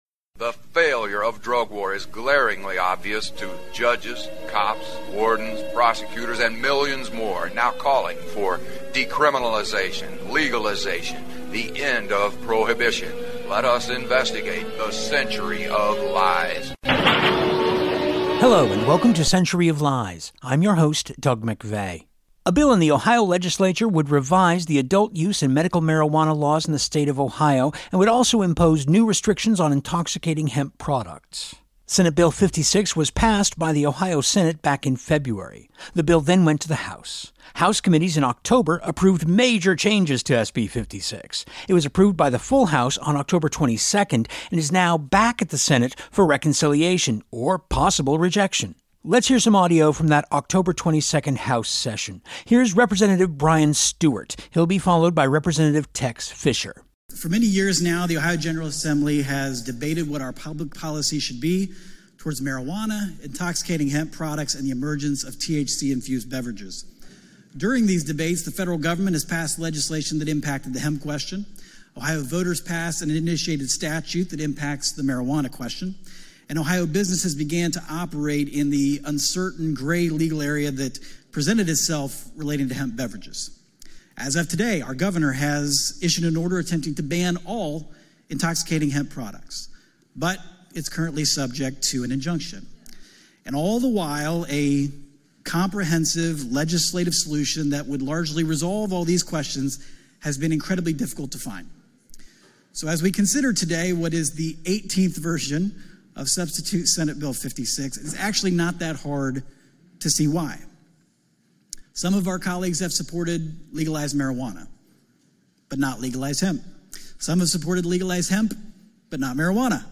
On this edition of Century we hear some of that final floor debate including submissions by Representatives Brian Stewart, Tex Fischer, Eric Synenberg, Haraz Ghanbari, Jennifer Gross, and Desiree Tims. play pause mute unmute KBOO